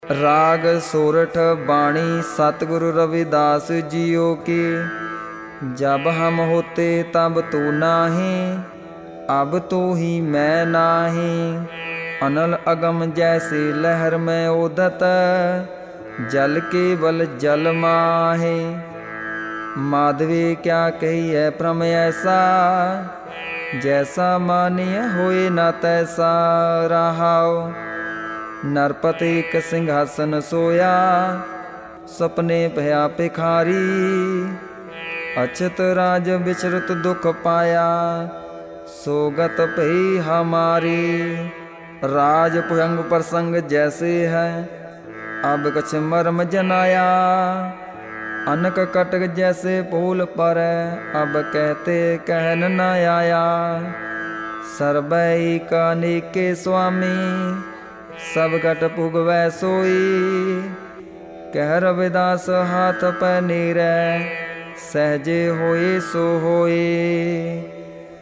ਰਾਗੁ ਸੋਰਠਿ ਬਾਣੀ ਭਗਤ ਰਵਿਦਾਸ ਜੀ ਕੀ